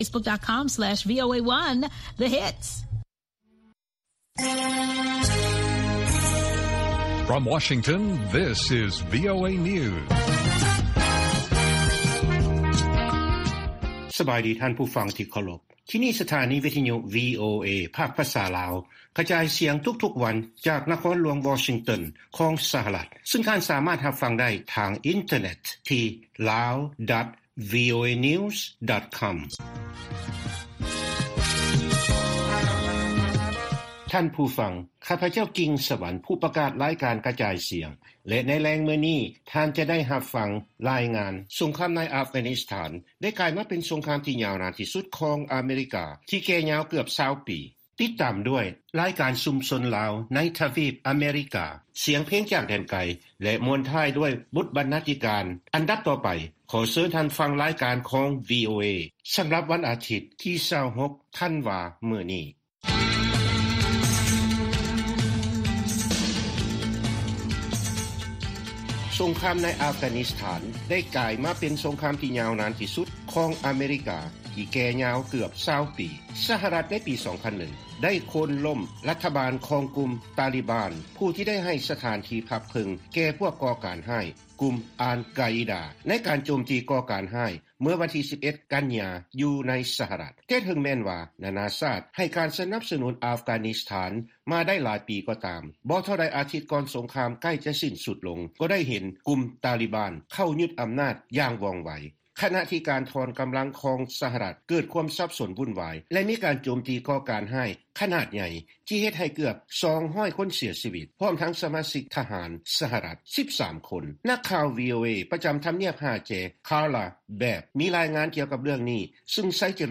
ລາຍການກະຈາຍສຽງຂອງວີໂອເອ ລາວ
ວີໂອເອພາກພາສາລາວ ກະຈາຍສຽງທຸກໆວັນ ເລື້ອງສຳຄັນໃນມື້ນີ້ ມີ: 1.